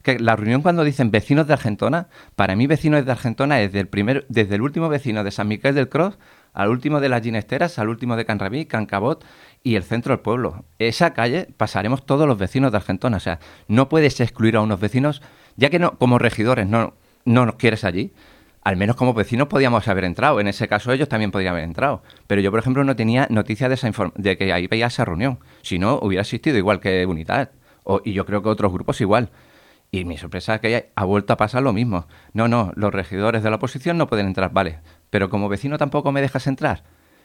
Avui, el regidor del Partit Popular, Fran Fragoso, hi ha tornat a fer referència durant el seu torn d'entrevista política a Ràdio Argentona.